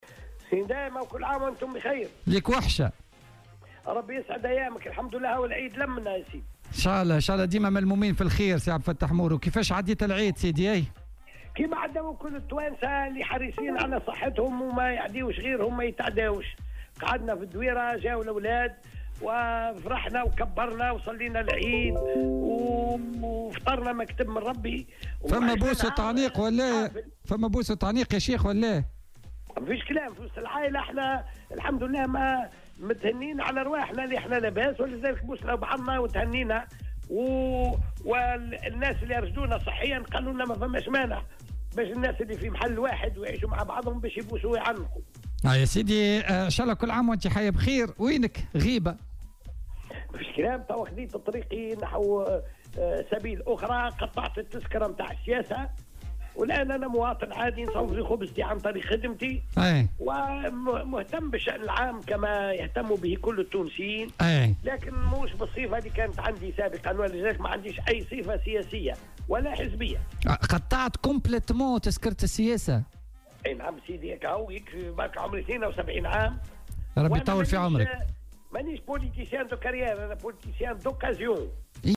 وأوضح في مداخلة له اليوم في برنامج "بوليتيكا" أنه قام بتقبيل أفراد عائلته وعناقهم مثلما دأبت عليه العادة في مثل هذه المناسبات، مضيفا " في وسط العائلة الحمد لله متهنين على أرواحنا لاباس.. والناس اللي أرشدونا صحيا قالوا مافماش مانع باش الناس اللي في نفس الدار يعيّدوا على بعضهم".وأكد مورو أنه "قام بتمزيق تذكرة السياسة" واعتزل العمل السياسي ولم تعد له اي صفة سياسية أو حزبية وأنه سيعود إلى عمله الأصلي.